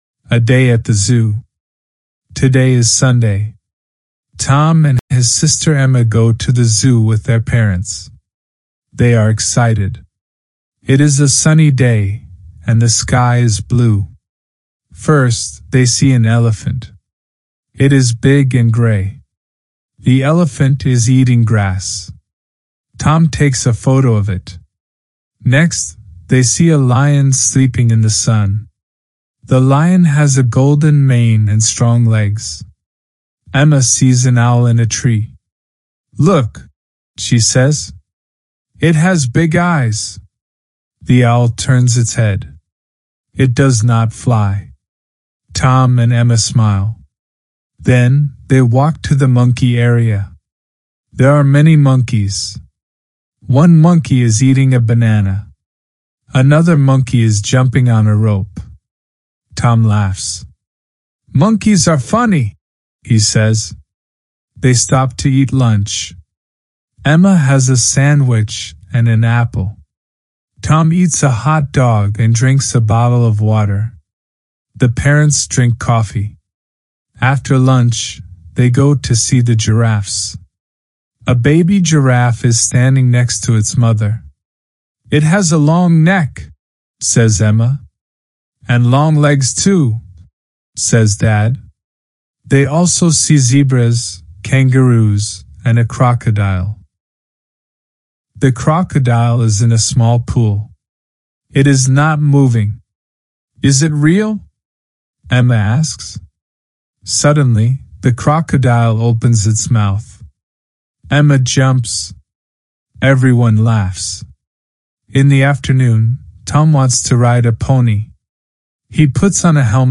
LISTENING PRACTICE
Click on the left picture below to listen to the slow audio version, or on the right picture for the normal-speed version.